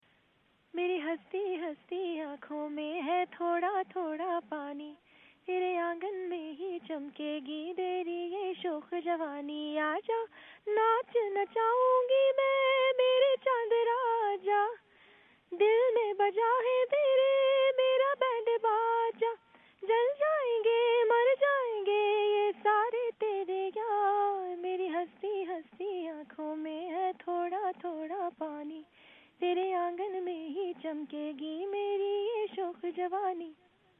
جب فلم جلیبی کی ہیروئین ژالے سرحدی نے گانا سنایا